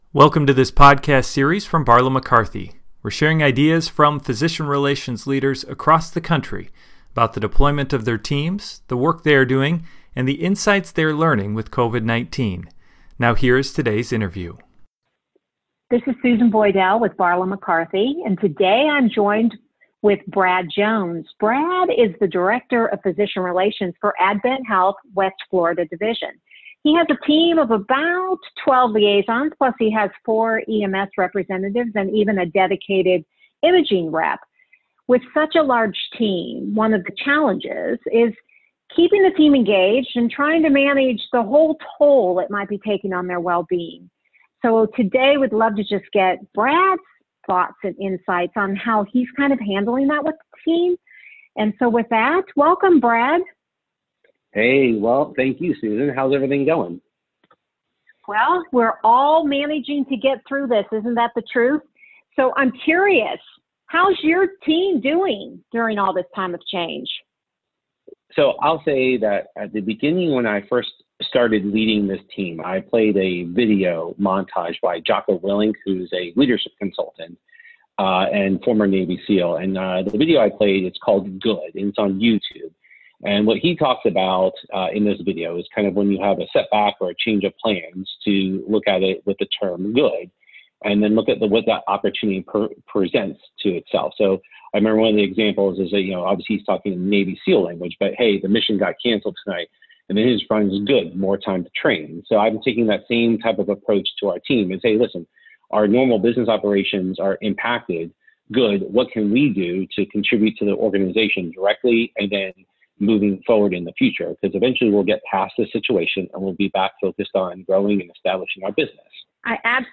B/Mc Podcast: COVID-19 and Physician Relations – Leadership Interview #8